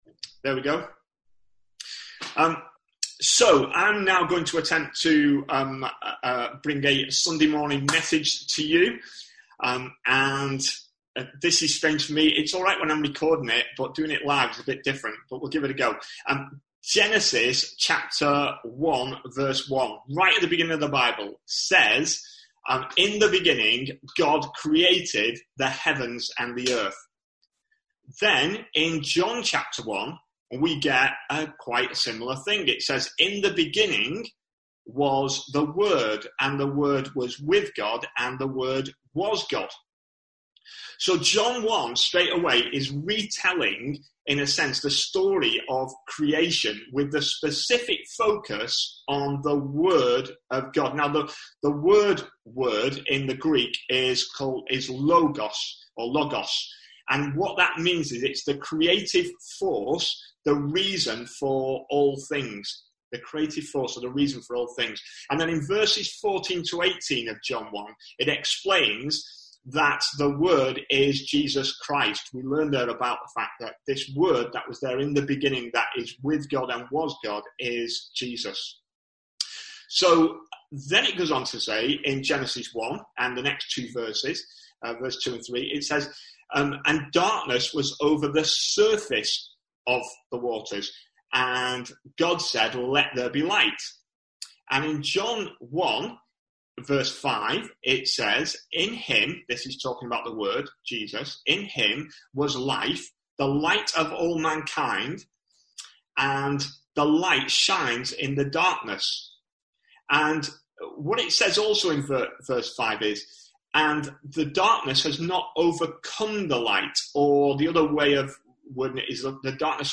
A sermon preached on 12th April, 2020.